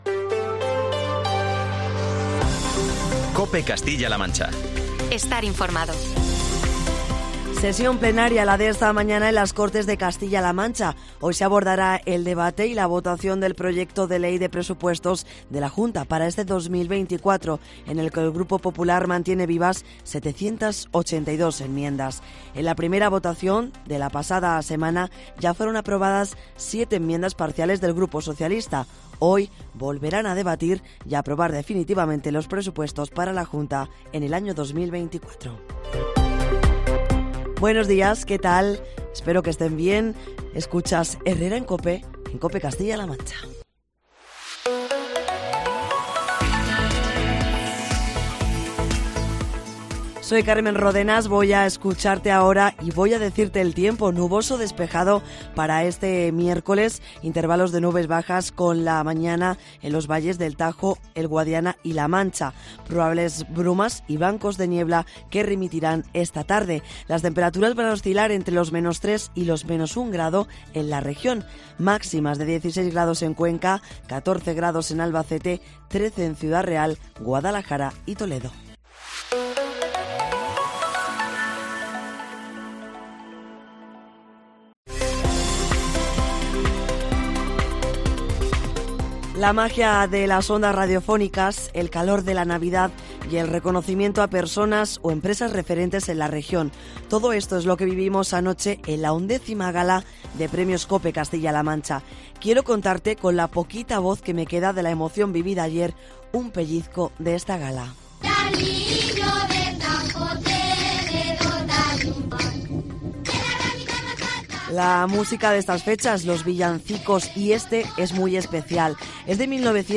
El Centro Cultural San Marcos de Toledo fue el escenario de una velada emotiva y llena de pinceladas navideñas.